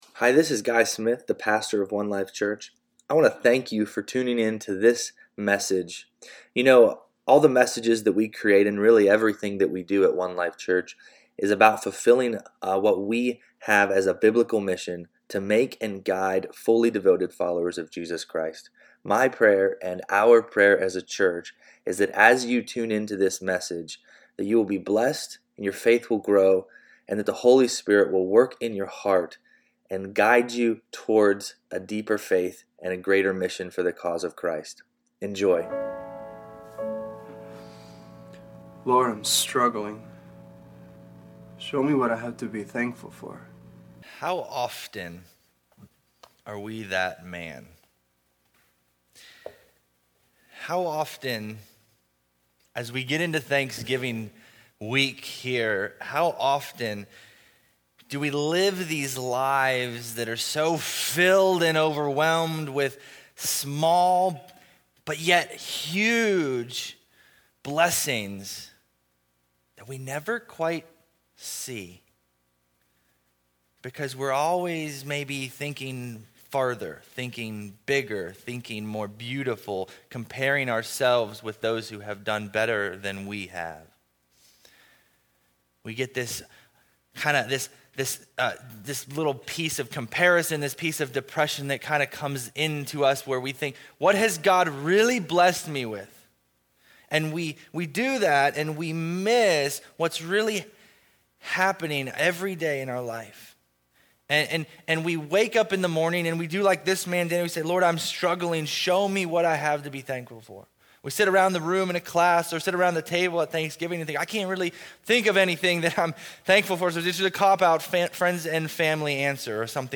Thankful-Sermon.mp3